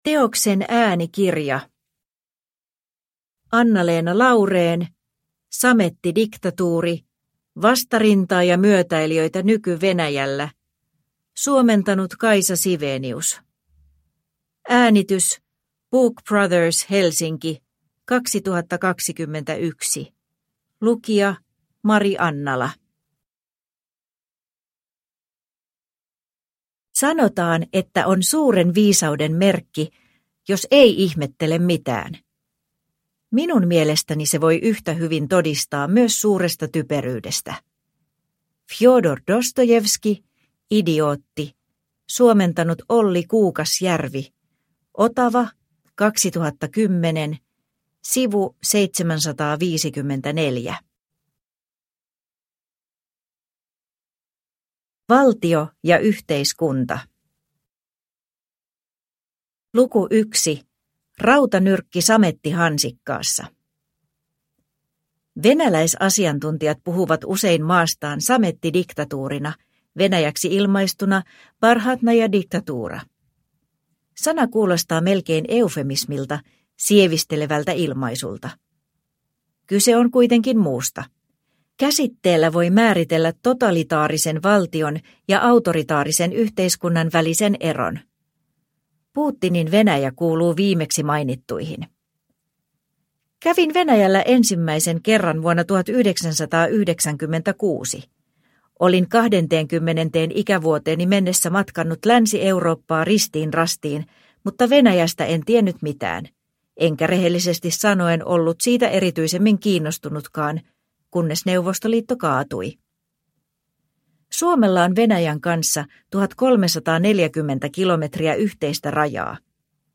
Samettidiktatuuri – Ljudbok – Laddas ner